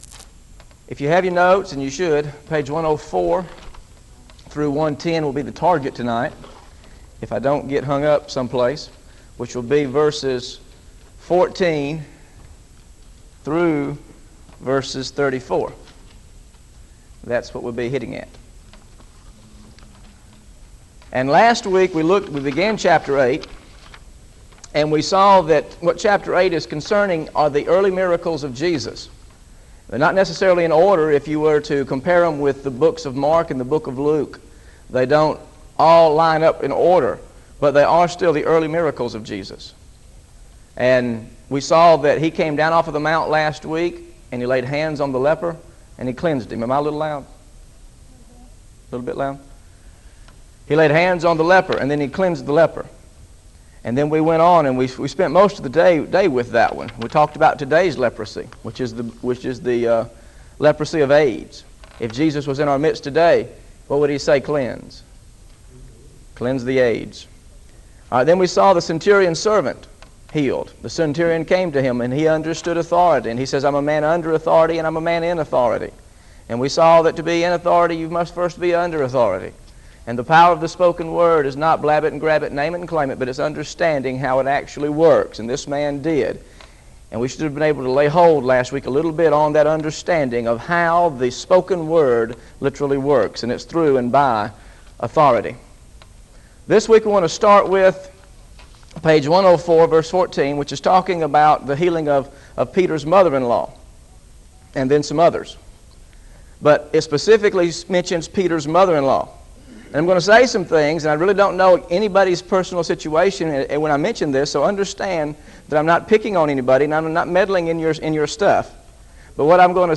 GOSPEL OF MATTHEW BIBLE STUDY SERIES This study of Matthew: Matthew 8 Pt 2 How to Value Souls over Possessions is part of a verse-by-verse teaching series through the Gospel of Matthew.